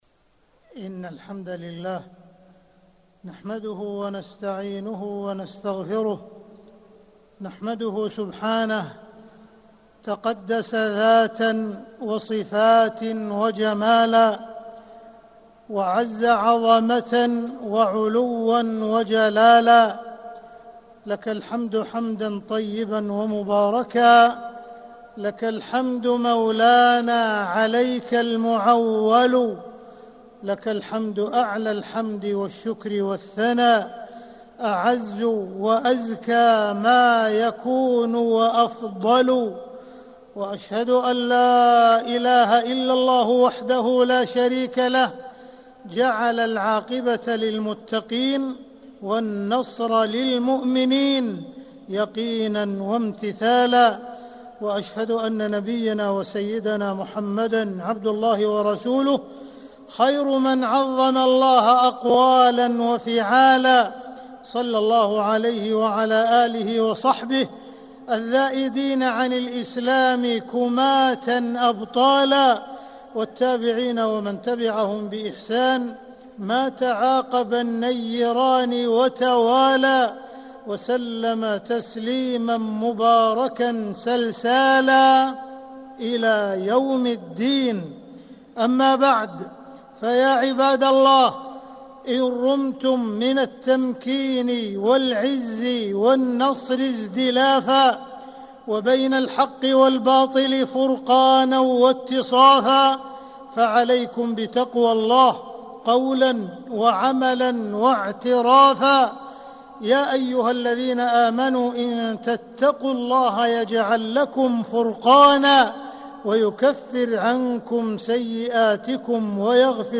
مكة: الهجرة وعاشوراء - عبد الرحمن بن عبدالعزيز السديس (صوت - جودة عالية